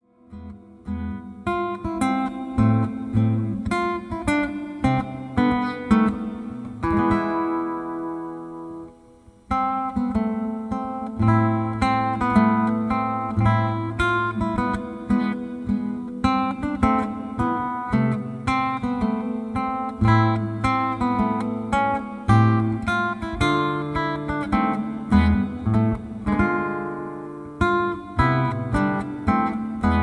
Soothing and Relaxing Guitar Music